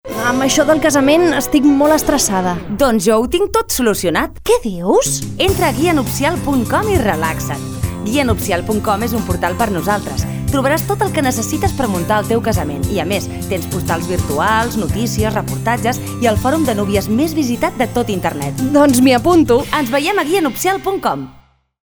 A continuació us presentem les falques de publicitat que s’han emès a ràdio Flaix Bac: